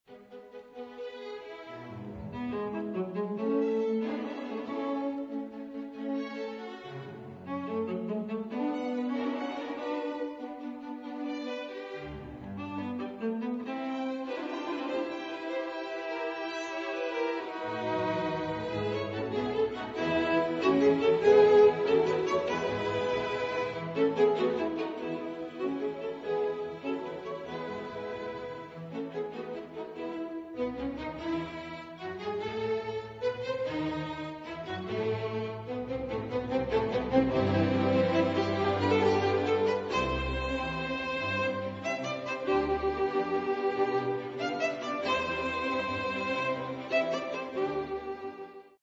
Rejcha composed mainly classical chamber music in spirit of Mannheim School, technically sofisticated, often with surprizing elements of future romantic style.
Concerto E Major for cello and string orchestra (3.